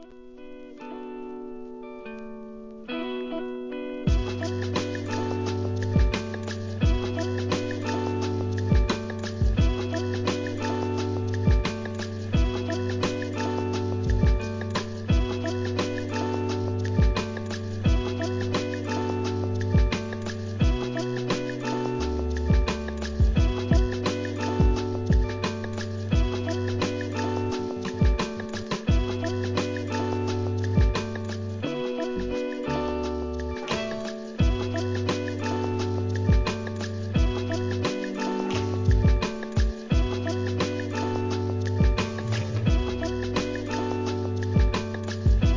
ドイツはライプチヒで活動する電子音楽トリオ!!